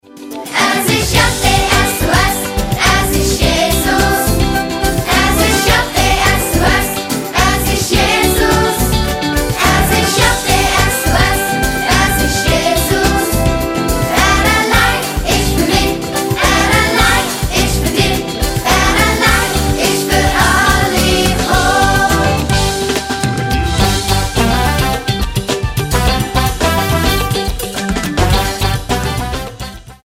Mundart-Worshipsongs für Kids